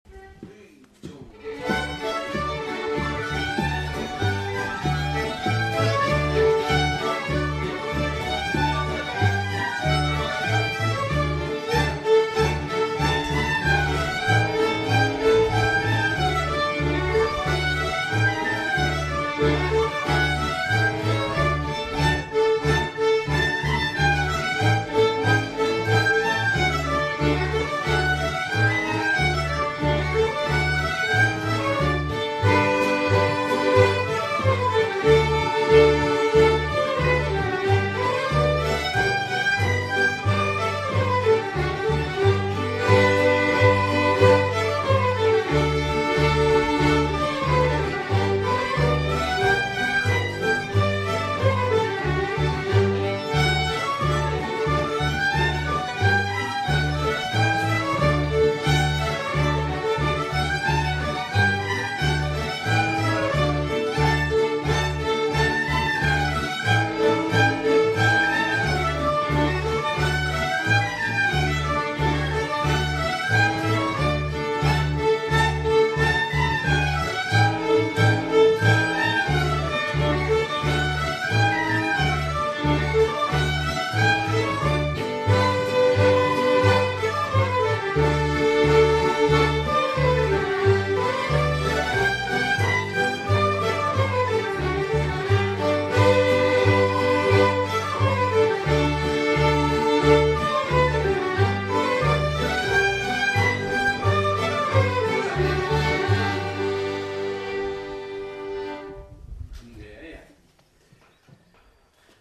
Musikken p� �lejren p� Ly�